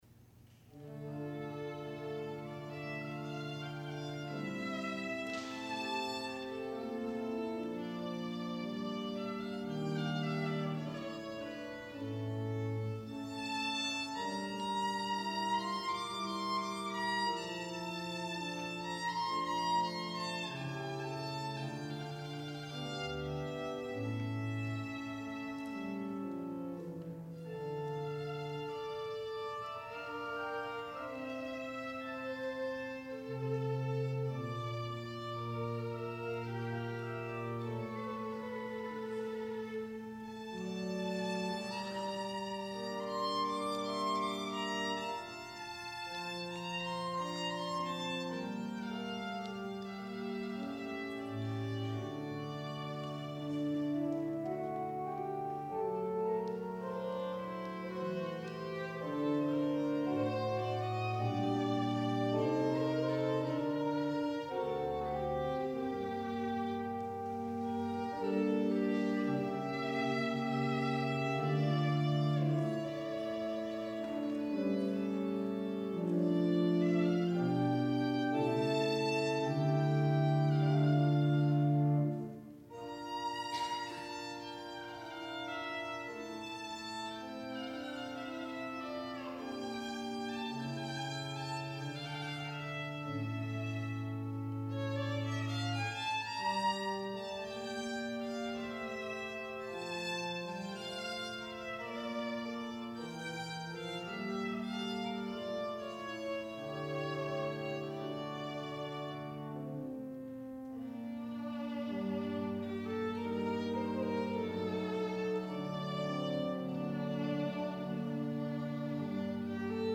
violin
organ